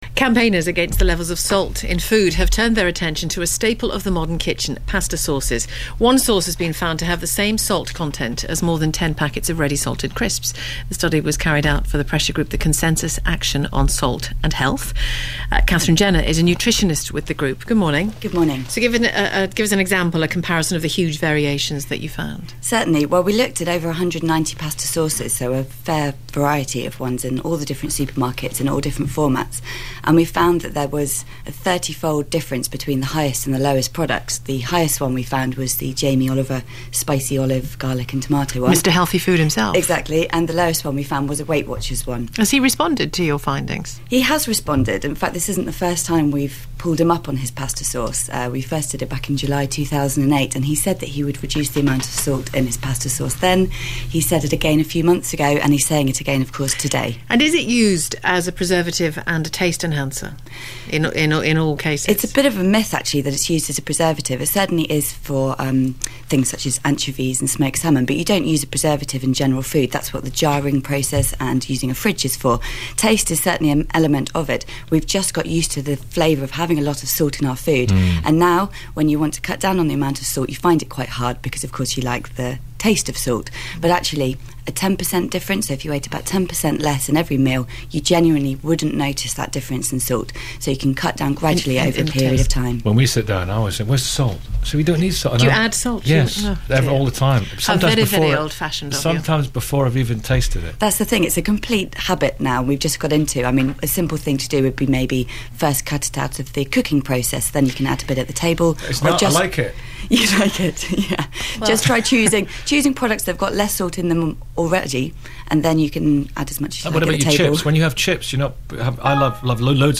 interview on BBC's 5 Live [MP3 4.96 MB]